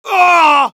Voice file from Team Fortress 2 Spanish version.
Heavy_painsevere03_es.wav